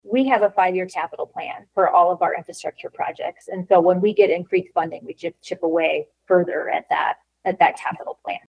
DNR director, Kayla Lyon, was asked at yesterday’s (Thrusday) Natural Resources Commission meeting how the money will be spent.